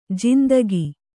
♪ jandagi